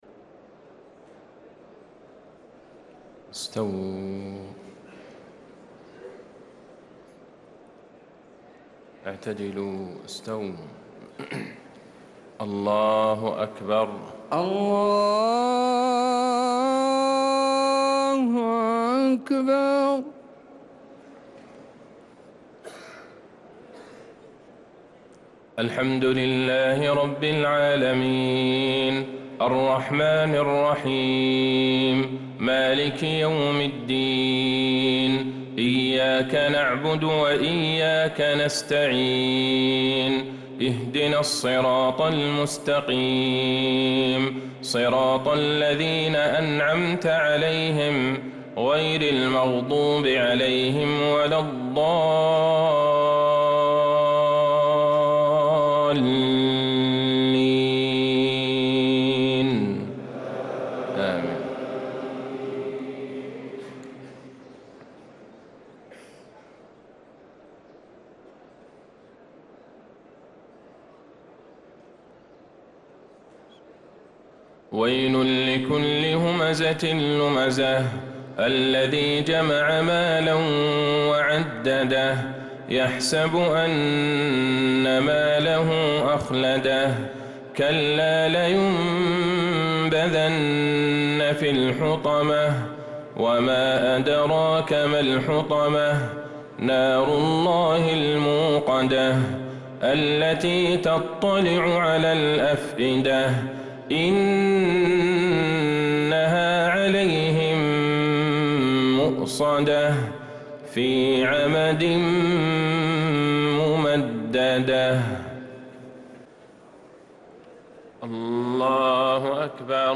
صلاة المغرب للقارئ عبدالله البعيجان 22 شعبان 1445 هـ